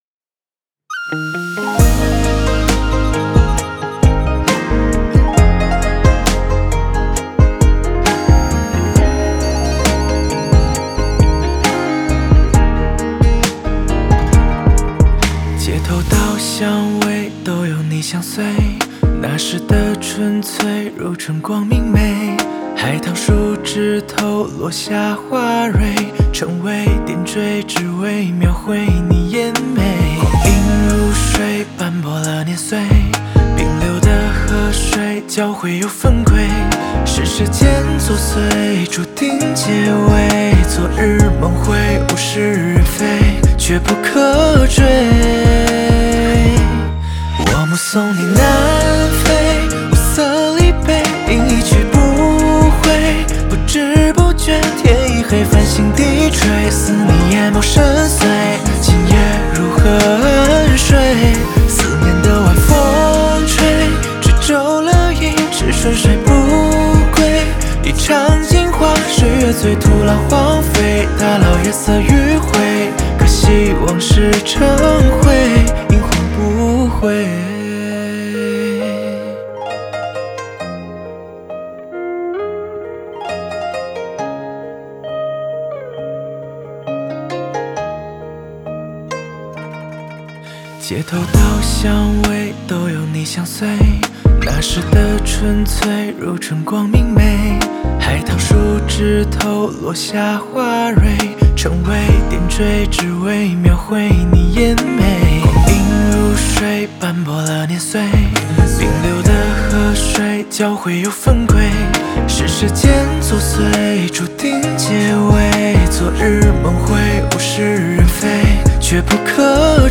Ps：在线试听为压缩音质节选，体验无损音质请下载完整版
吉他
Bass
和声